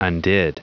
Prononciation du mot undid en anglais (fichier audio)
Prononciation du mot : undid